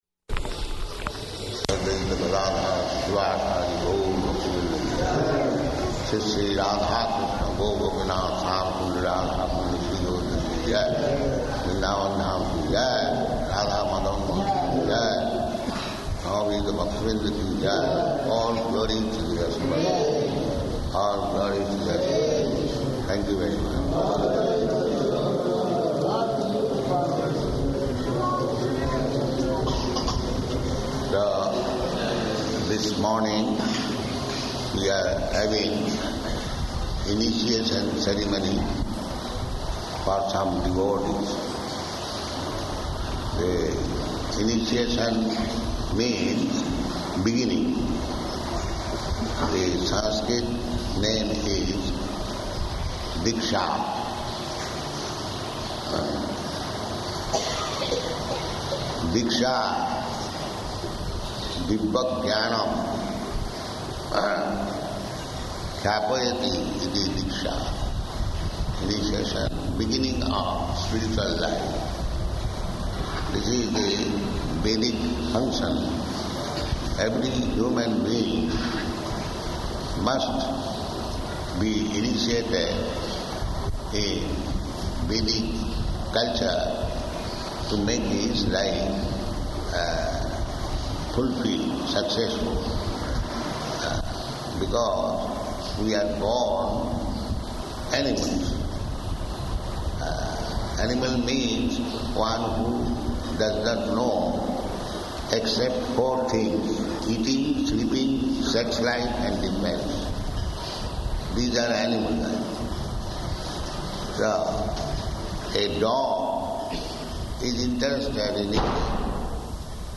Initiation Lecture
Location: Hyderabad